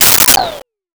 Comical Pop And Swirl
Comical Pop and Swirl.wav